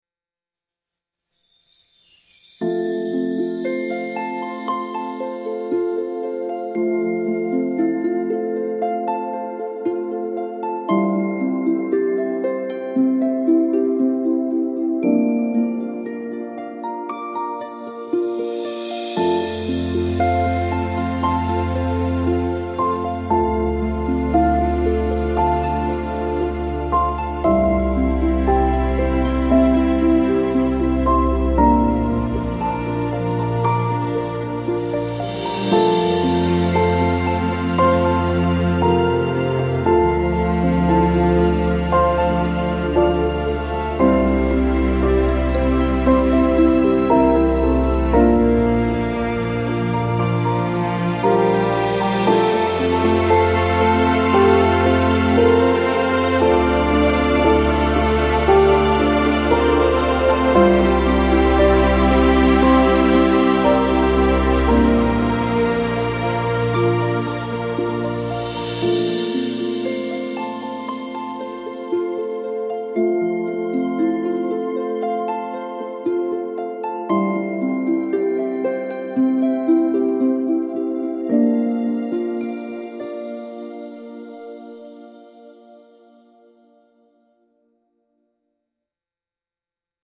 uplifting spiritual piece with angelic tones and hopeful rising melody